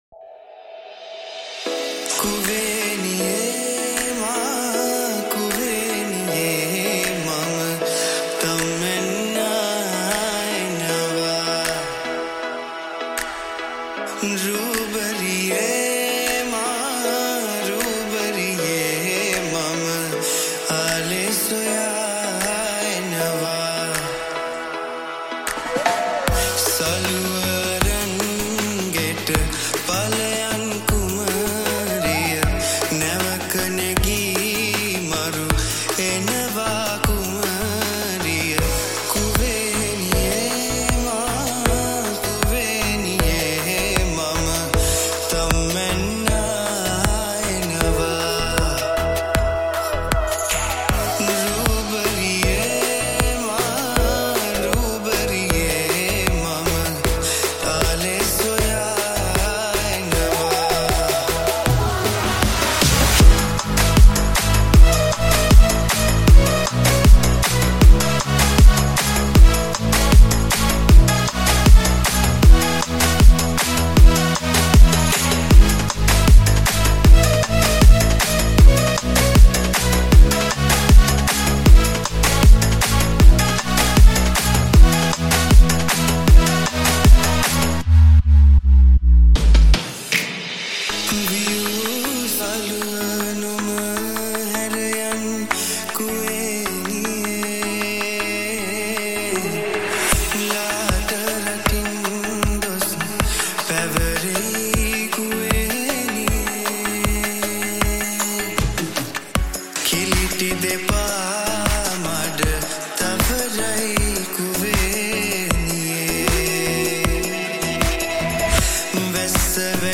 High quality Sri Lankan remix MP3 (5).